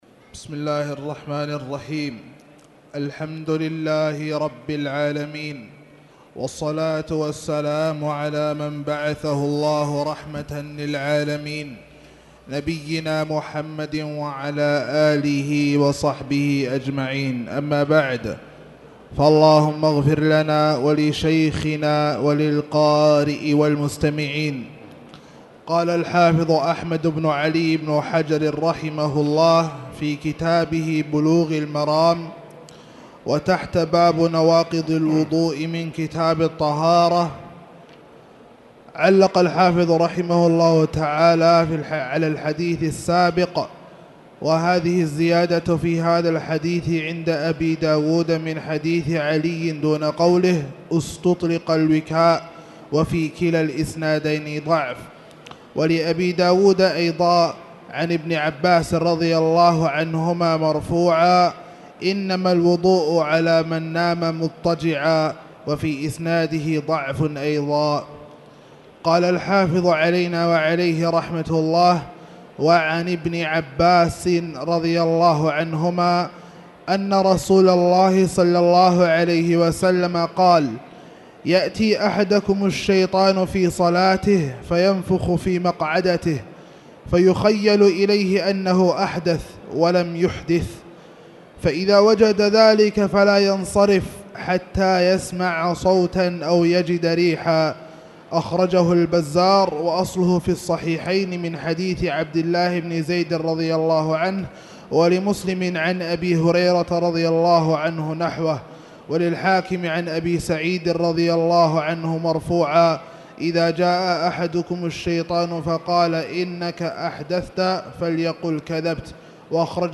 تاريخ النشر ٢٠ ربيع الثاني ١٤٣٨ هـ المكان: المسجد الحرام الشيخ